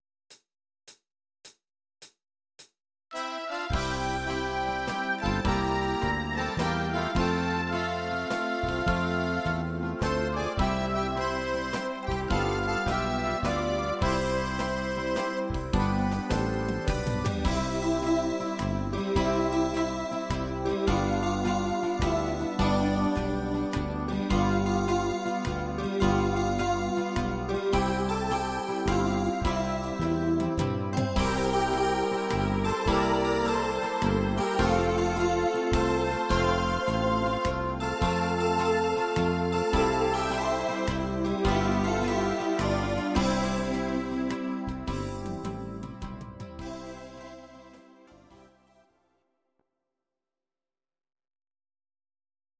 Audio Recordings based on Midi-files
Our Suggestions, Pop, German